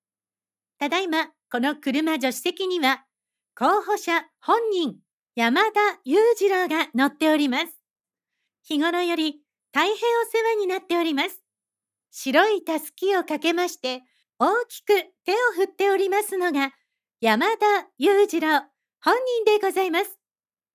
ヴォイスサンプル【候補者が乗っているver】
候補者が選挙カーに乗っているバージョンのウグイス嬢の台詞
選挙ウグイス嬢のしゃべり方は、特徴的です。